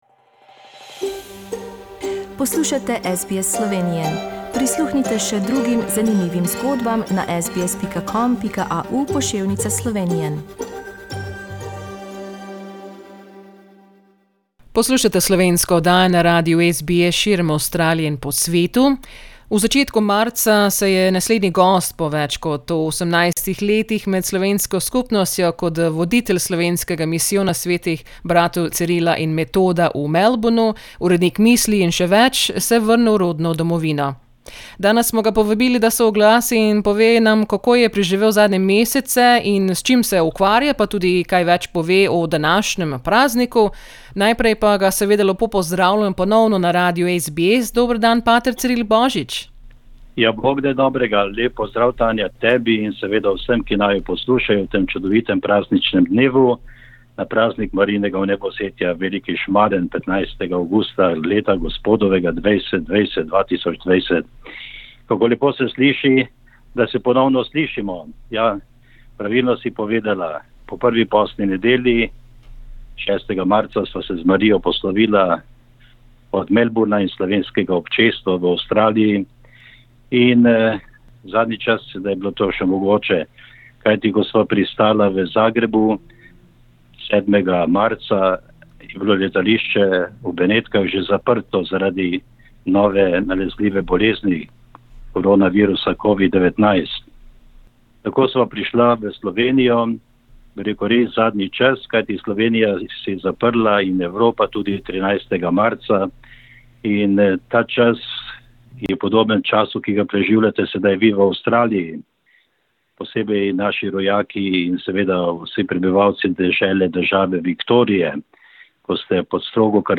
Danes se je oglasil iz Kamnika in spregovoril o delu in o današnjem prazniku Marijinega vnebovzetja.